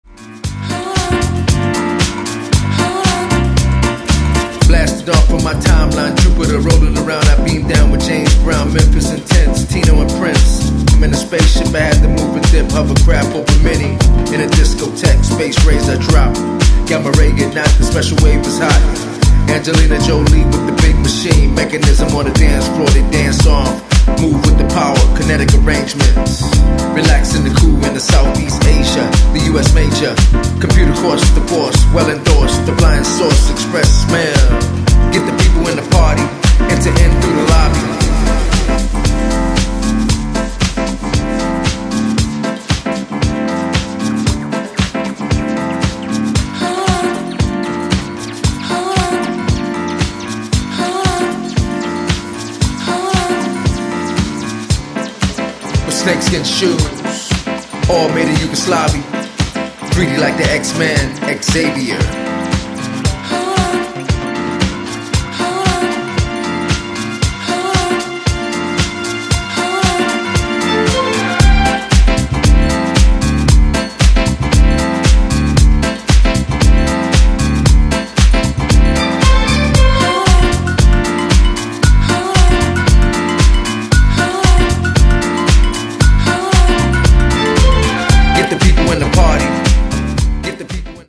ジャンル(スタイル) NU DISCO / DISCO / HOUSE